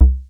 RESO C4.wav